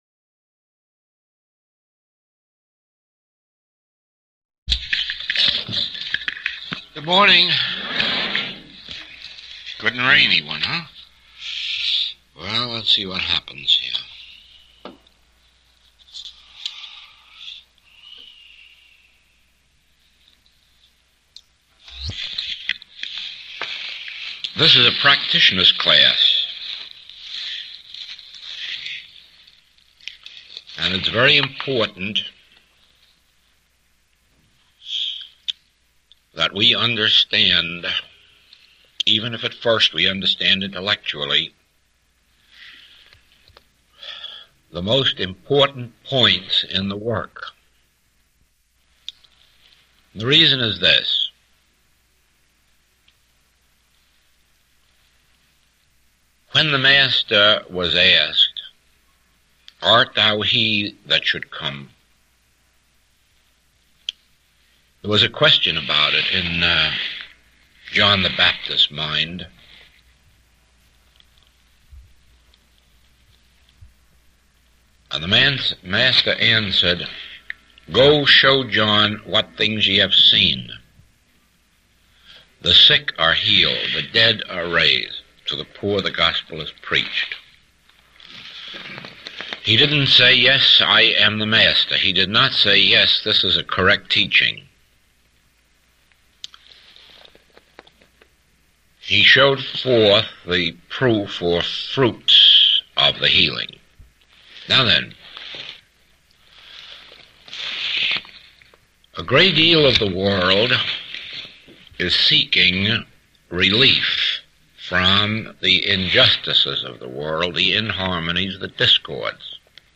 Recording 60A is from the 1954 Chicago Practitioner Class.